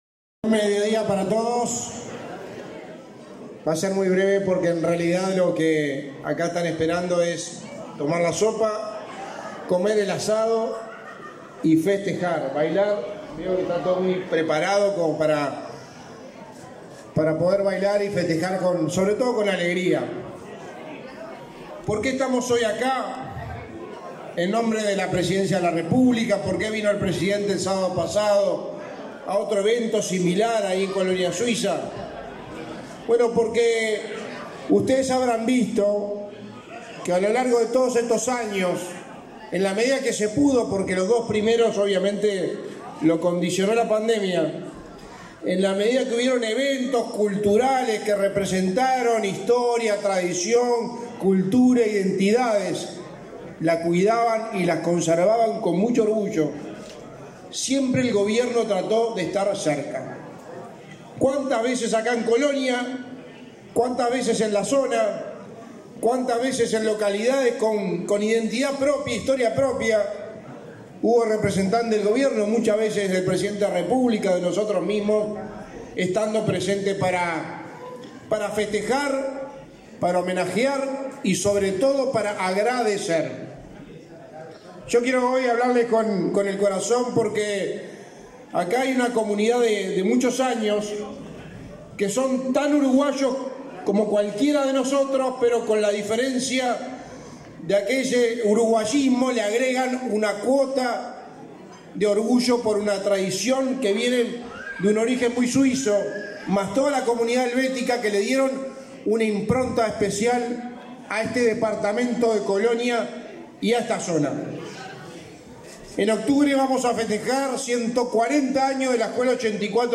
Palabras del secretario de la Presidencia, Álvaro Delgado
Palabras del secretario de la Presidencia, Álvaro Delgado 13/08/2023 Compartir Facebook X Copiar enlace WhatsApp LinkedIn El secretario de la Presidencia de la República, Álvaro Delgado, participó, este 13 de agosto, en el aniversario de la ciudad de Nueva Helvecia, en el departamento de Colonia.